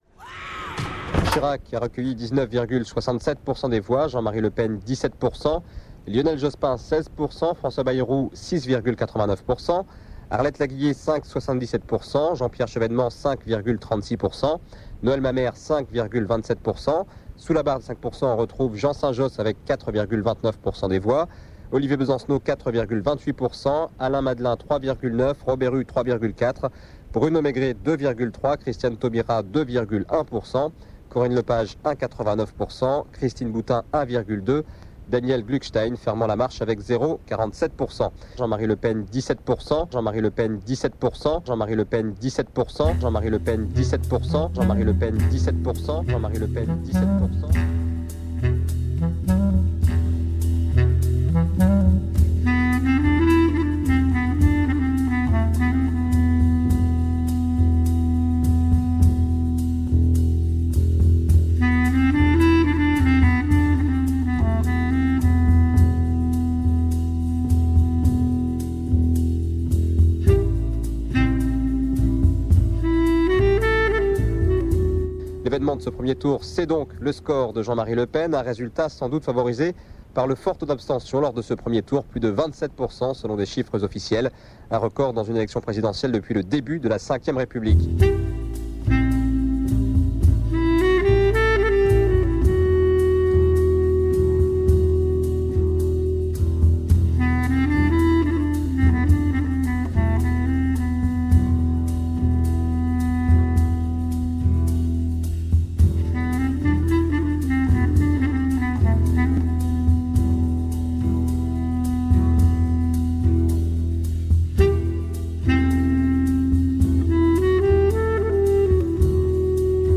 L’émission radiophonique (au format mp3)
L’entretien radiophonique a été diffusé par la radio Fréquence Paris Plurielle dans le cadre de l’émission Les oreilles loin du front.